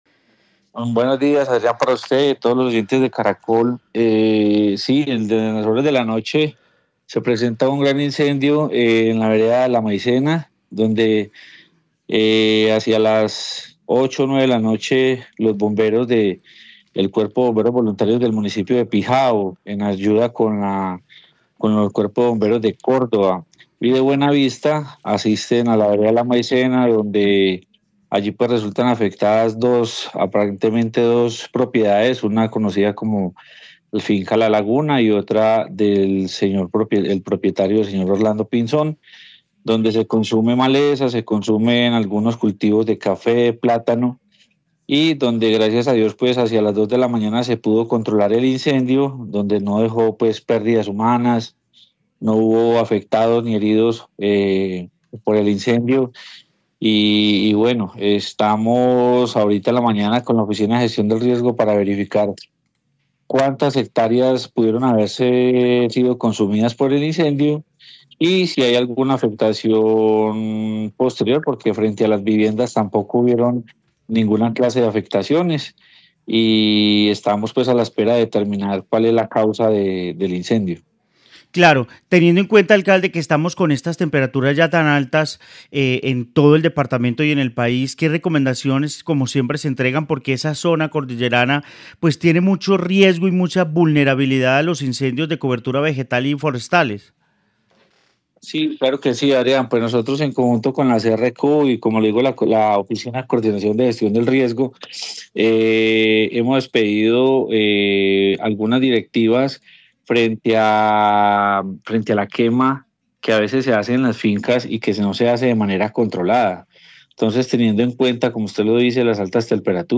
Jhon Jairo Restrepo, alcalde de Pijao, Quindío